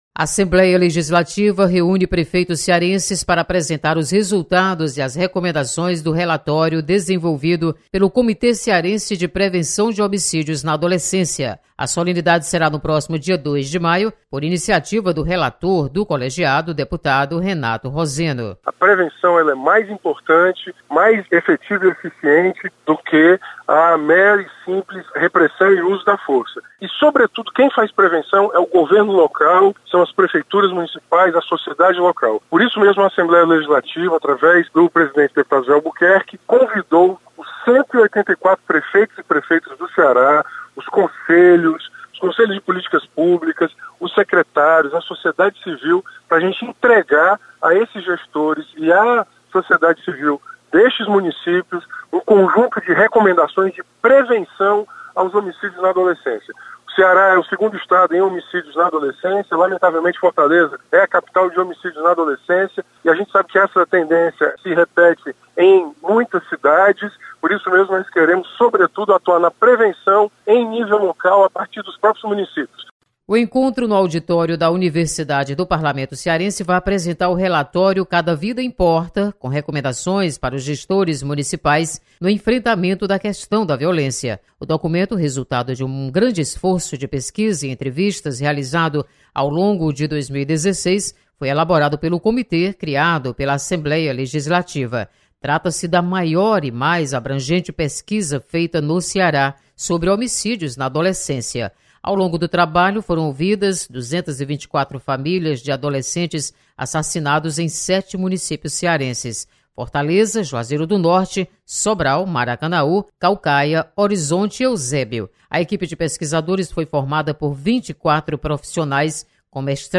Deputado Renato Roseno apresenta relatório do Comitê Cearense de Prevenção de Homicídios na Adolescência.